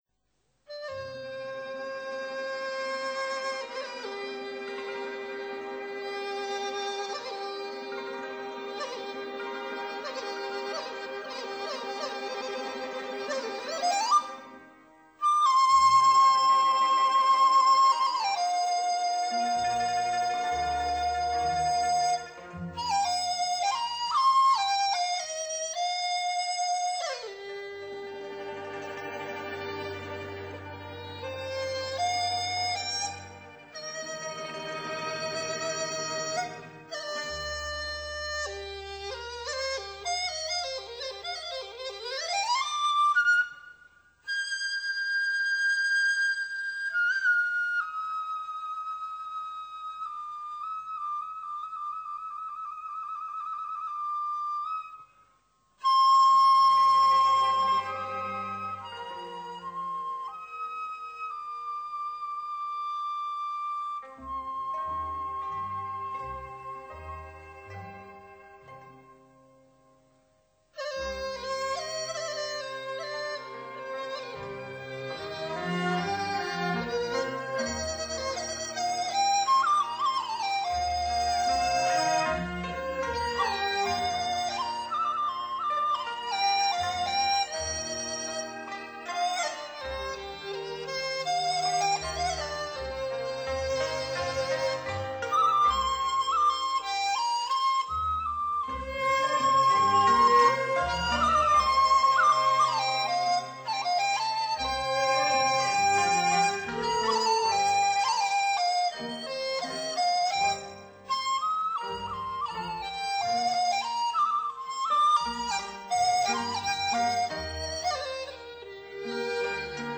它们囊括了当代中国最优秀的笛子演奏家演奏的最有代表性的笛子独奏曲。